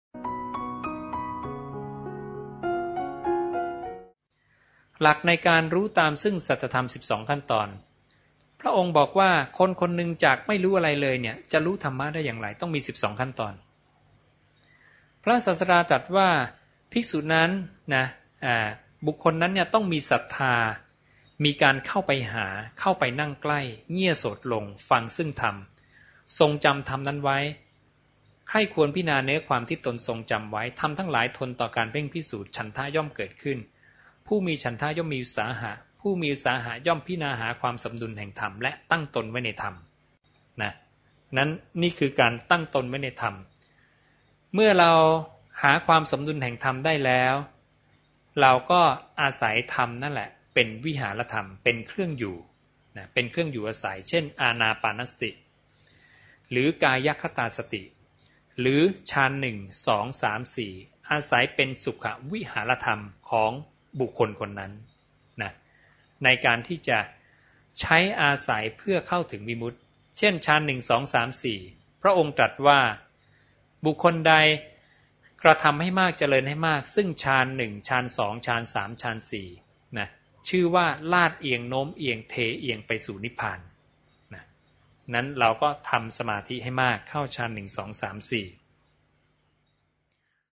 สนทนาธรรมค่ำเสาร์   27 ส.ค. 2554